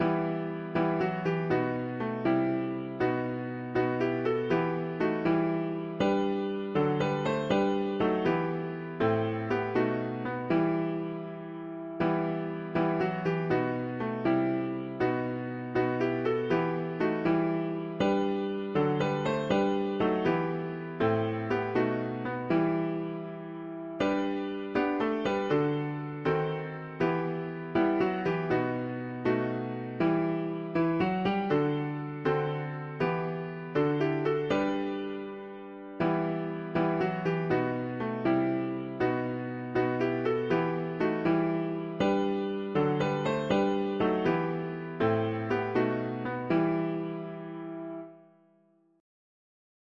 Key: E minor